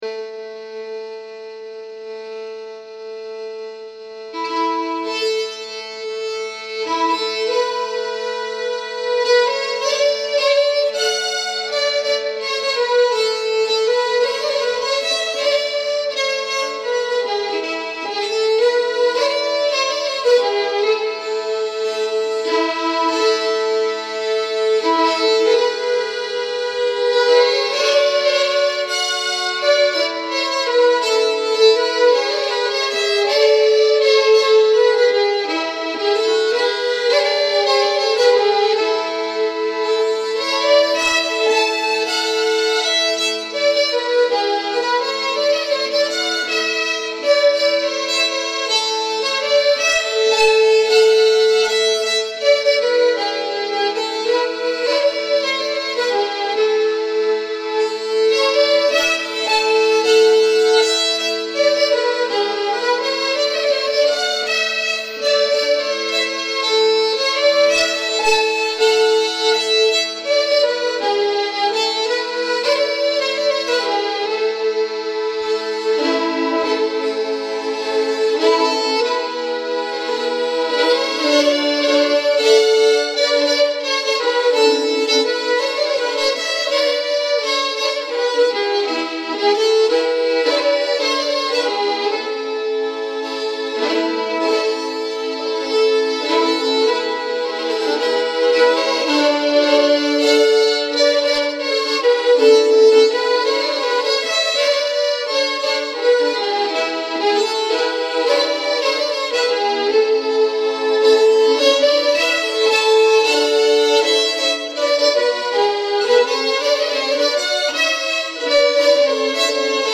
(Fiol)